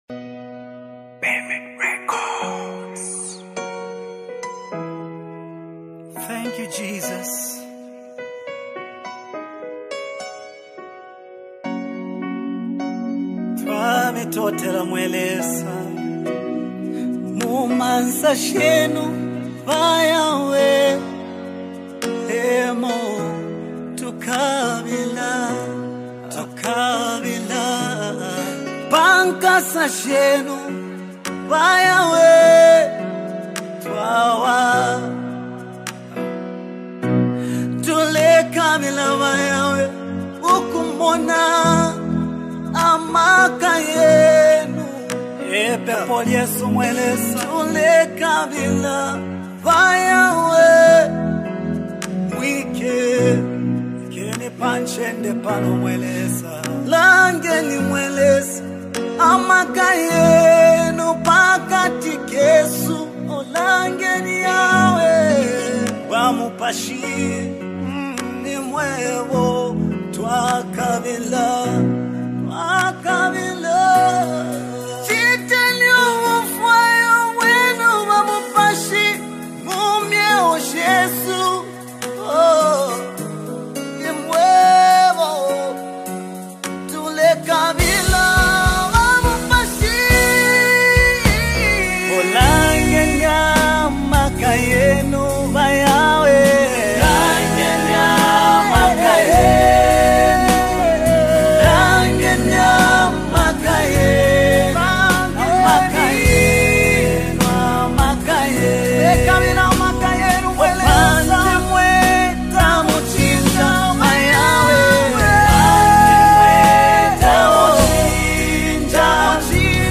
With its heartfelt lyrics and uplifting melody